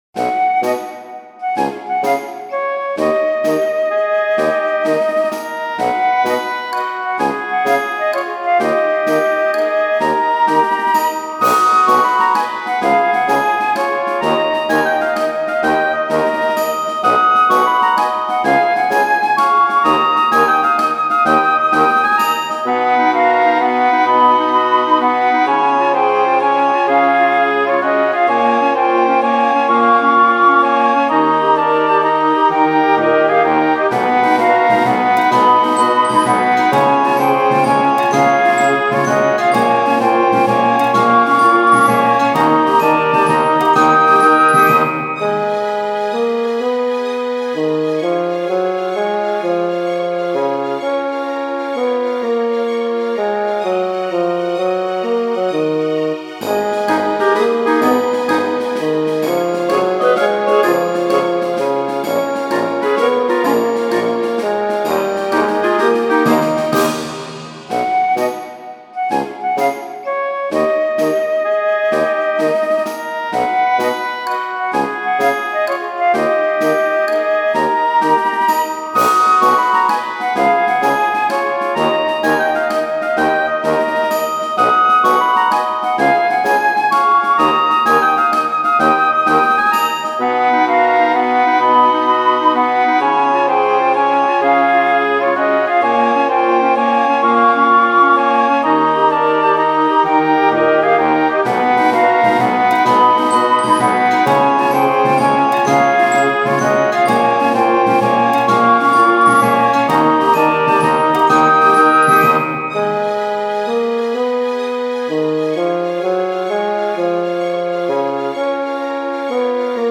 街 明るい にぎやか オーケストラ
賑やかなライトオーケストラ。